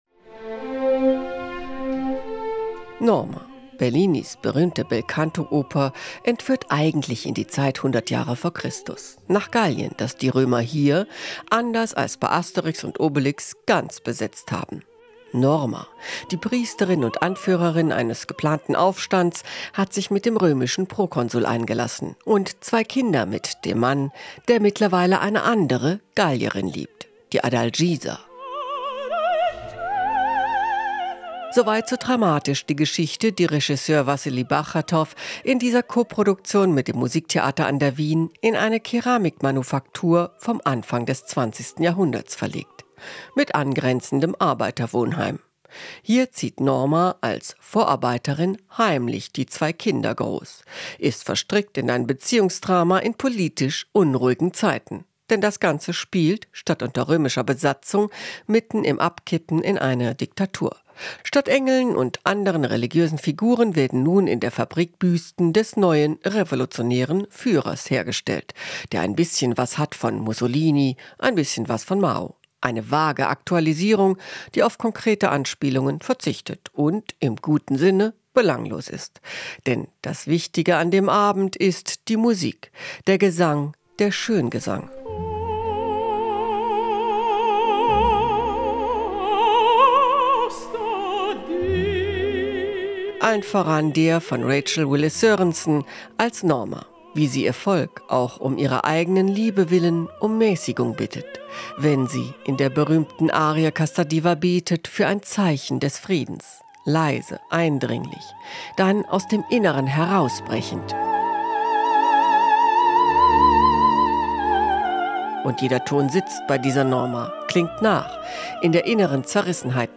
Der Wegweiser durch die weite Kulturlandschaft von Berlin und Brandenburg: Premieren- und Konzertkritiken, Film- und Musiktipps, Rundgänge durch aktuelle Ausstellungen.